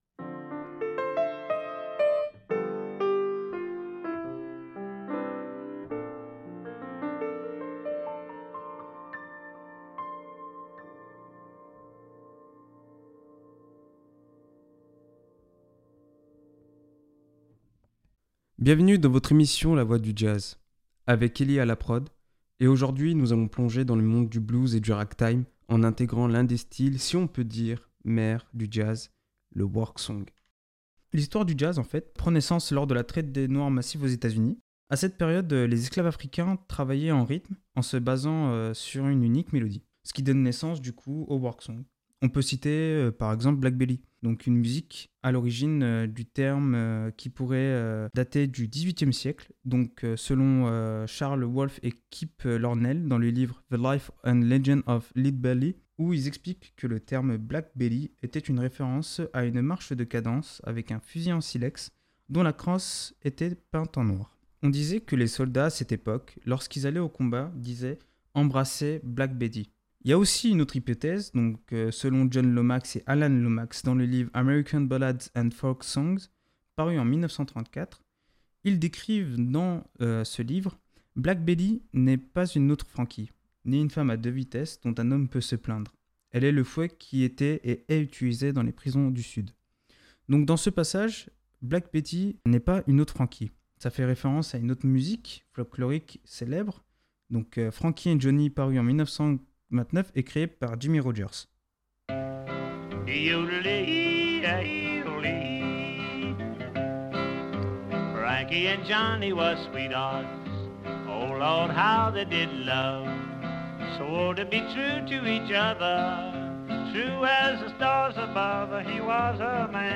Du work song, né dans les champs de coton, au blues, voix des émotions et des luttes du peuple afro-américain, jusqu’au ragtime, aux rythmes syncopés et dansants popularisés par Scott Joplin.